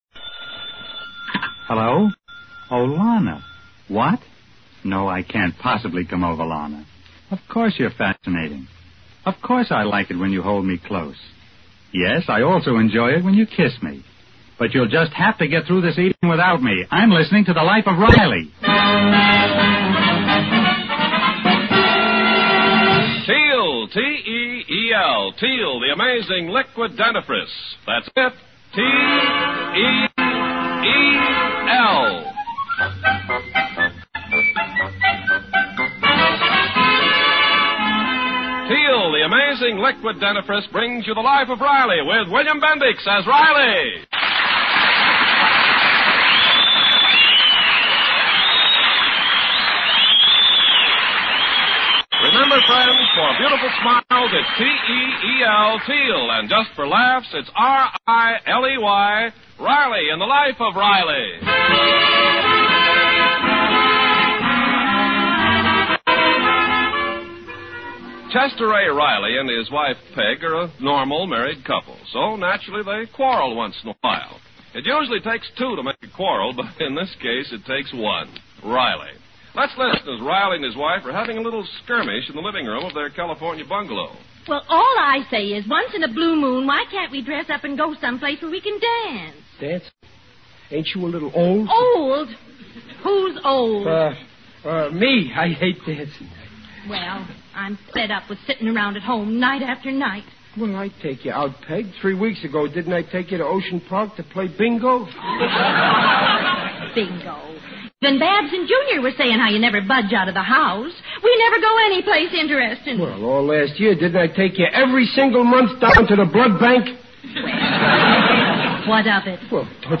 1940s radio sitcom.